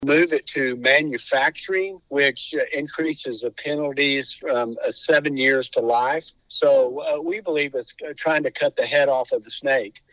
CLICK HERE to listen to commentary from sponsor Bill Weaver.